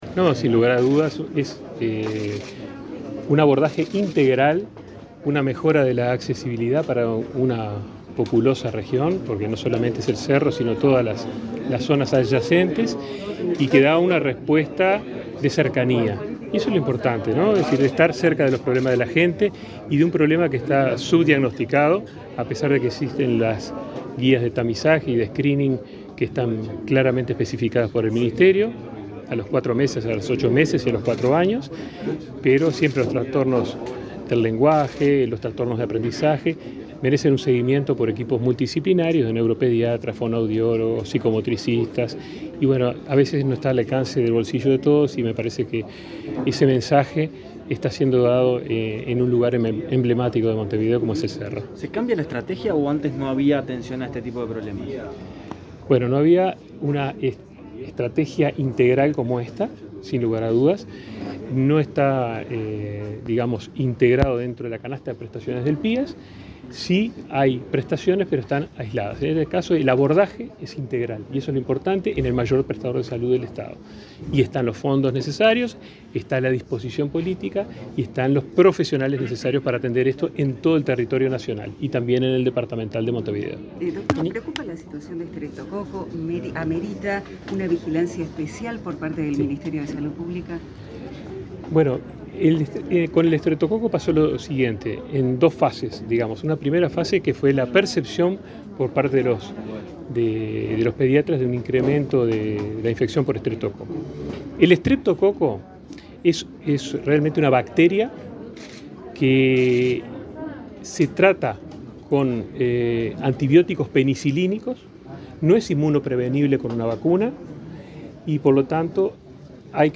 Declaraciones a la prensa del ministro de Salud Pública, Daniel Salinas
Declaraciones a la prensa del ministro de Salud Pública, Daniel Salinas 22/12/2022 Compartir Facebook X Copiar enlace WhatsApp LinkedIn El ministro de Salud Pública, Daniel Salinas, dialogó con la prensa, antes de participar del acto de inauguración de la Casa del Desarrollo de la Niñez, en el barrio Cerro de Montevideo.